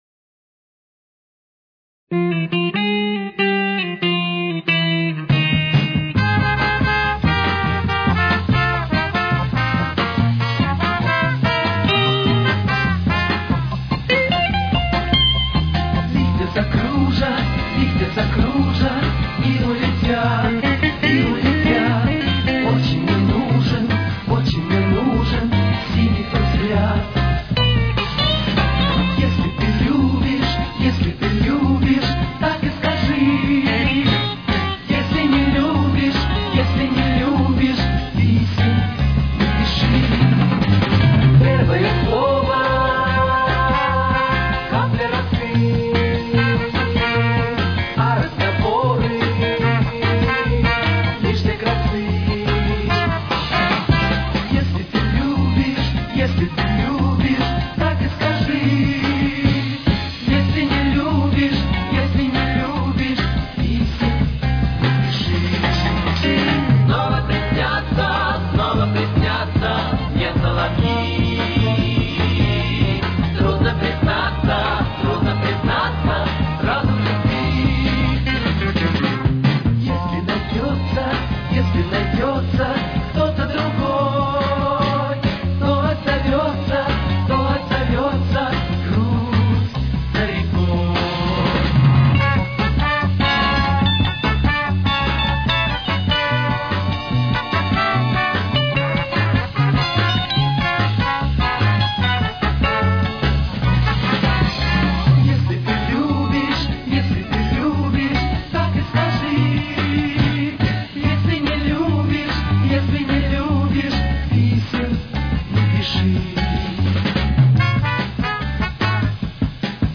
с очень низким качеством (16 – 32 кБит/с)
Фа минор. Темп: 150.